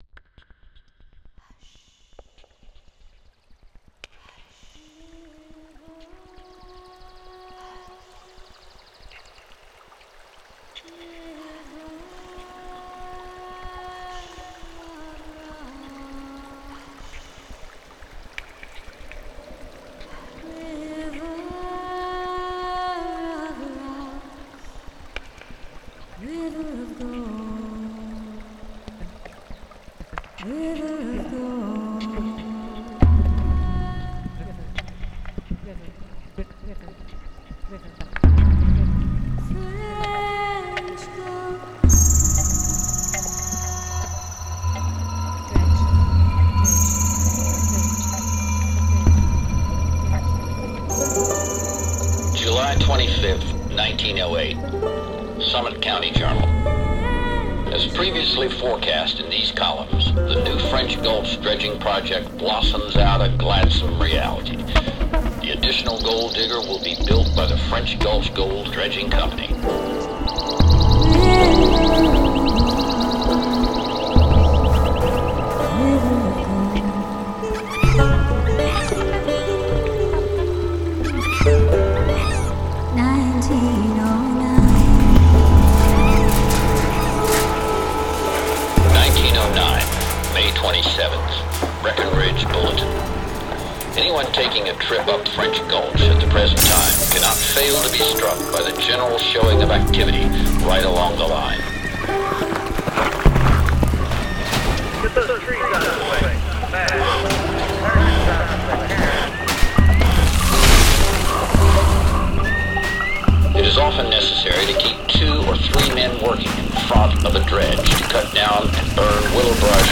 miner key. stereo mix
a site specific 6 channel sound installation at the Reiling Dredge. part of the 2024 Breckenridge International Festival of the Arts
vocals
horn
News voice
Worker voices
Tenor sax